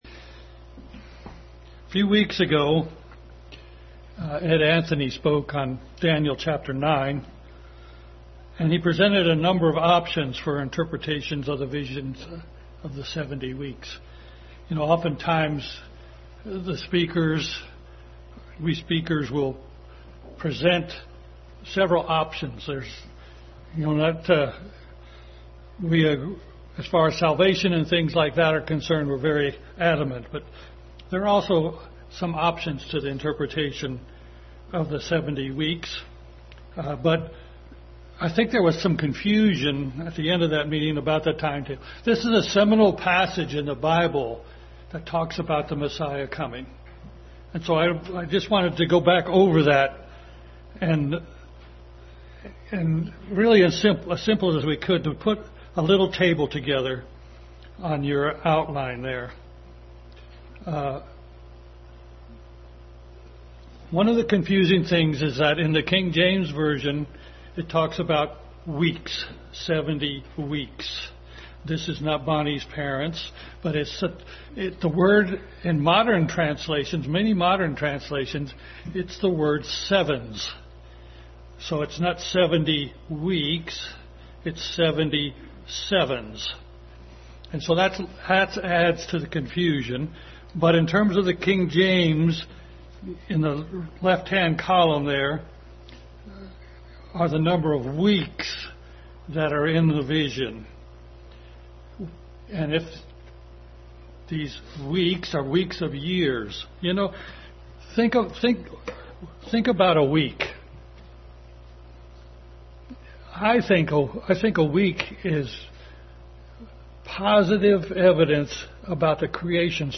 Daniel 10:1-21 Passage: Daniel 10:1-21 Service Type: Family Bible Hour